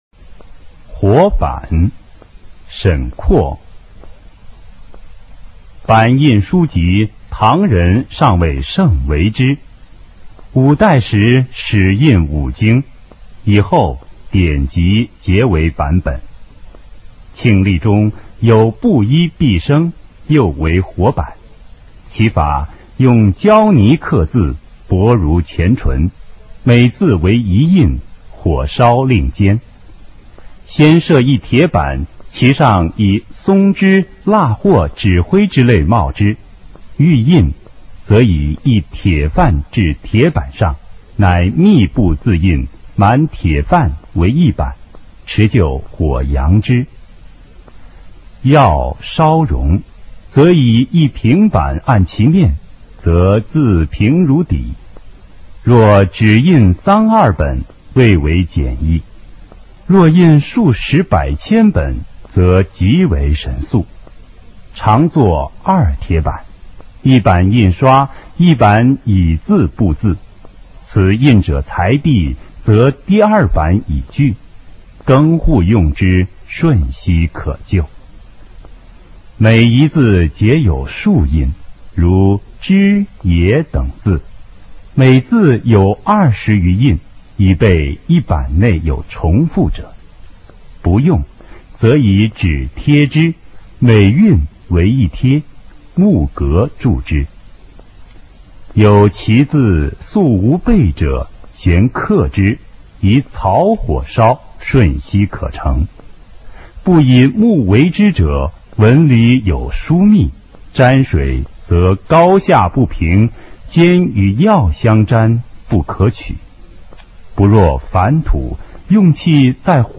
沈括《活板》原文和译文（含mp3朗读）